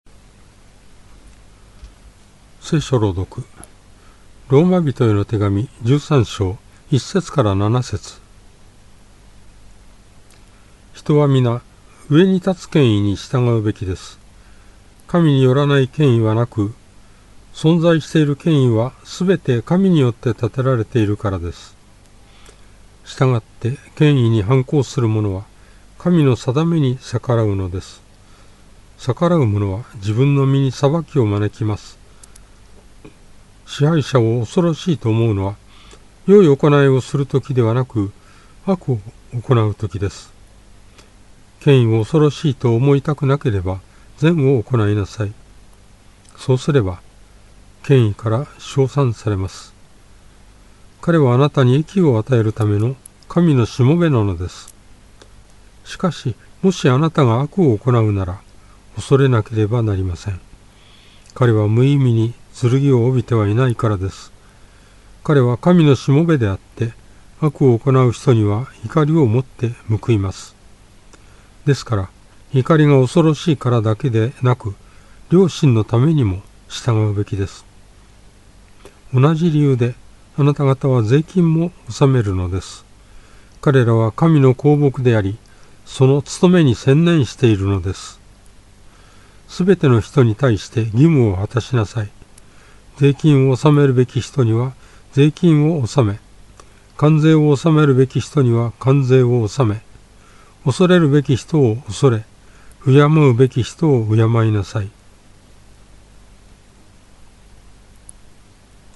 BibleReading_Roma13.1-7.mp3